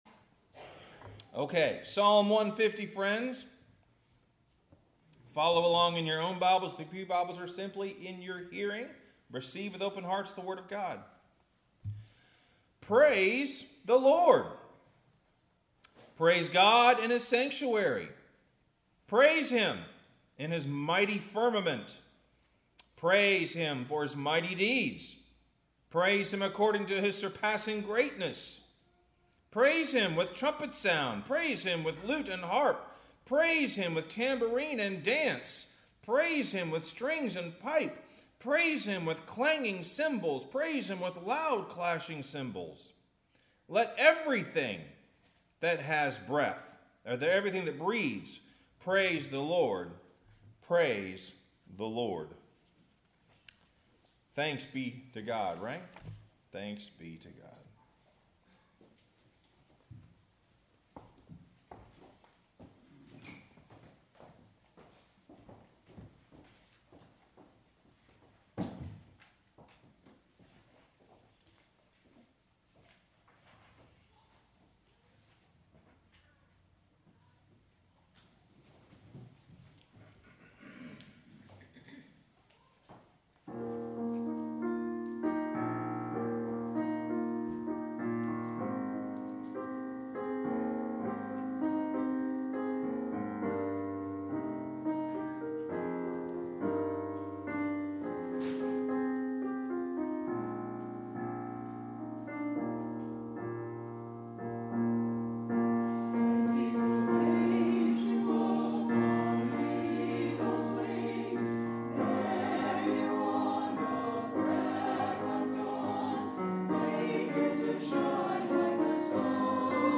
Sermon – What Wind Is in Your Sail?